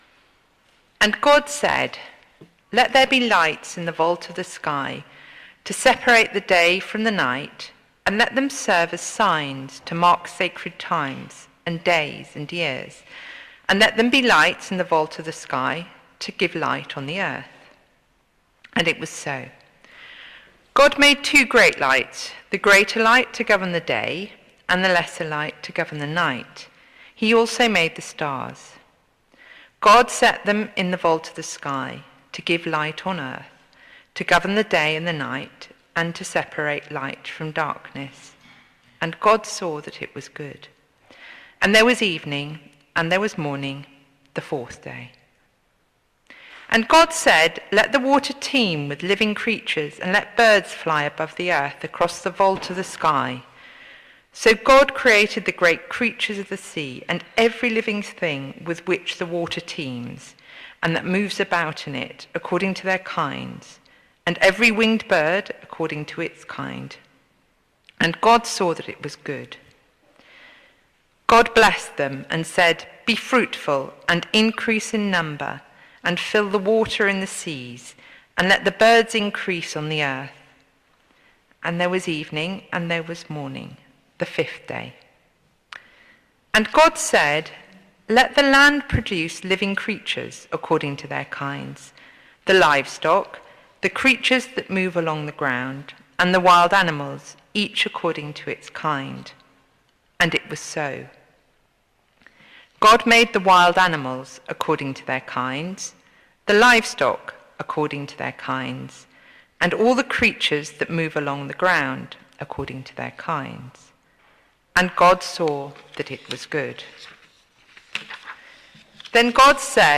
The readings are here as an audio file